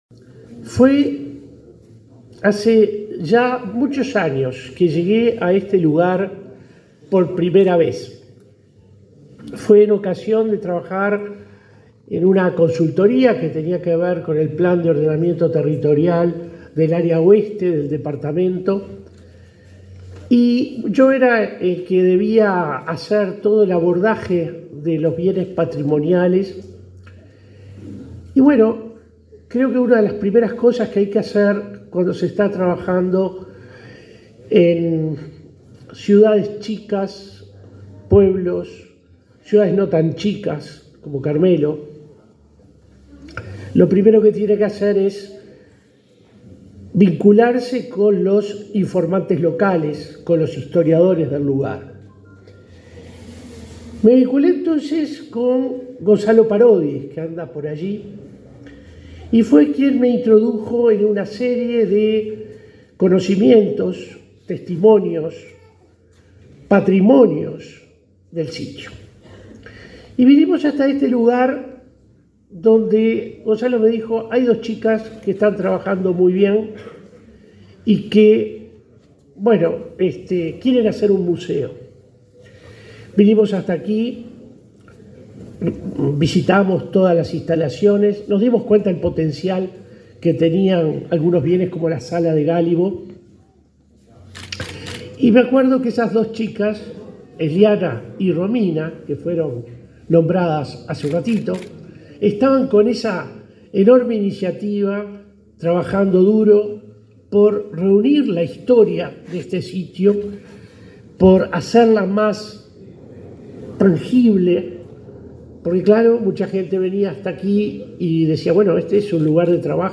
Palabras de autoridades en acto del MEC en Colonia